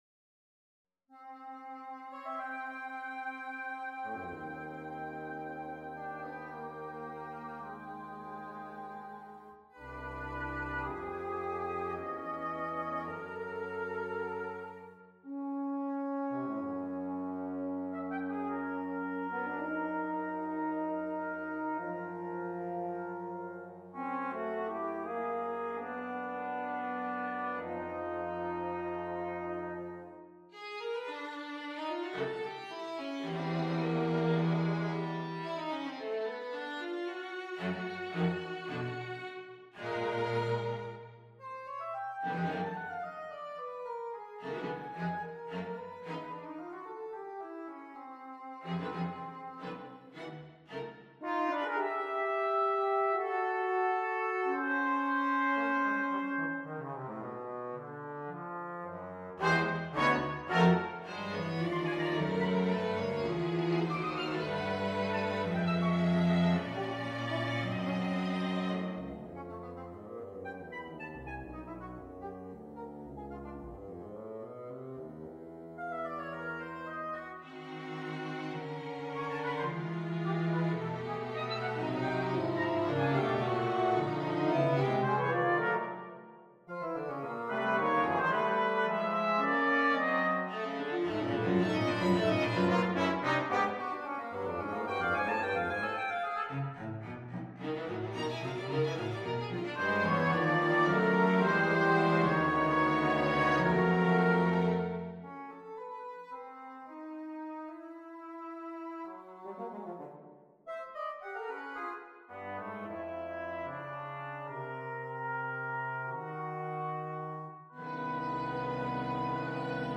Nonet No.4 for Winds, Brass, and Strings on a purpose-selected tone row Op.106 1. Allegretto 2. Lentissimo - Lento molto con anima - Tempo Primo 3. Allegro molto con brio - Adagietto Date Duration Download 13 June 2025 29'15" Realization (.MP3) Score (.PDF) 26.7 MB 2.0 MB